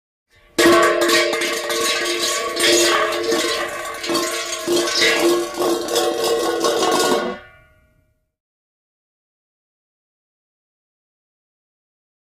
Hub Cap Falls On Asphalt, Rolls And Spins.